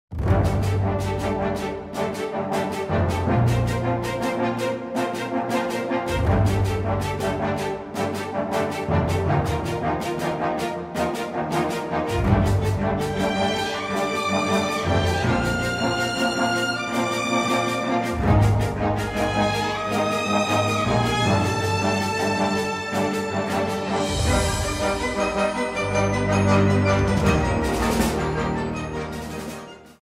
soundtrack
Trimmed to 30 seconds, with a fade out effect